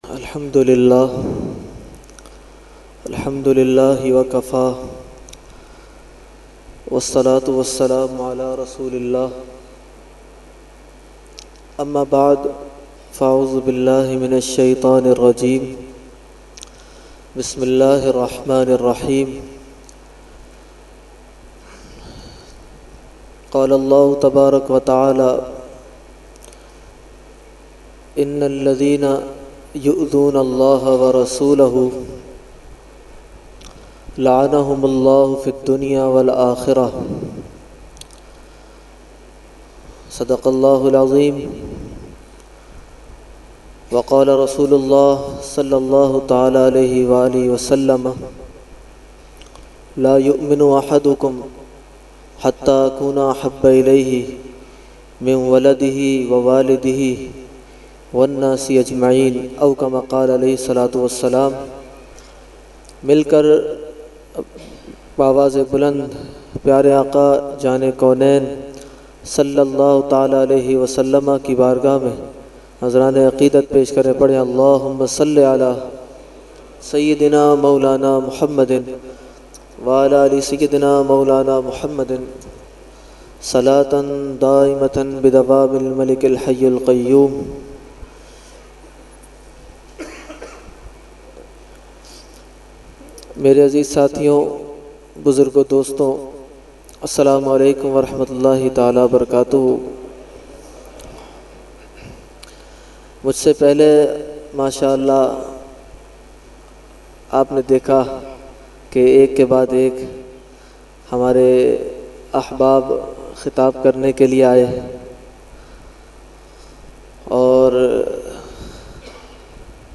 Jashne Subhe Baharan held 29 October 2020 at Dargah Alia Ashrafia Ashrafabad Firdous Colony Gulbahar Karachi.
Category : Speech | Language : UrduEvent : Jashne Subah Baharan 2020